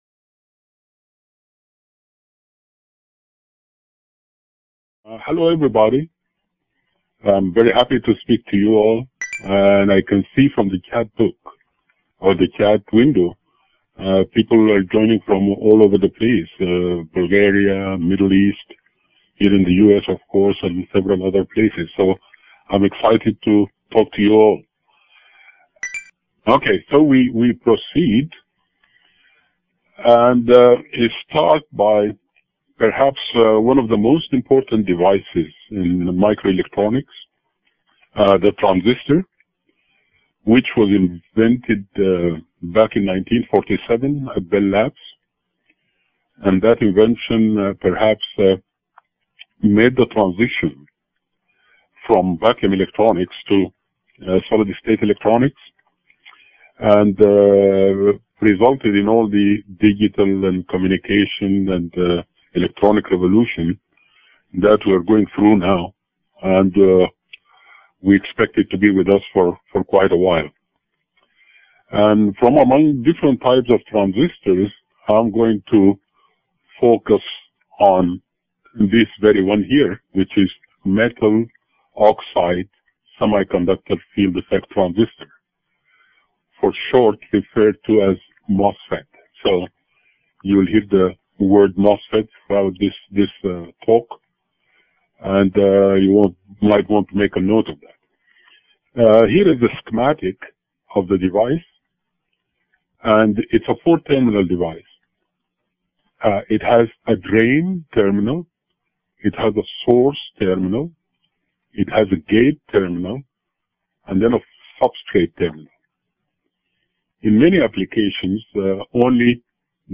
This webinar, from the Nanotechnology Applications and Career Knowledge (NACK) center, explores the impact of nanotechnology on microelectronics. The lecture begins with an overview of the history of miniaturization in electronics and Moore's law before addressing issues in manufacturing and new architectural and fabrication approaches in the industry. The webinar also covers the building blocks of nanoelectronics, including nanowires, nanotubes, and quantum dots.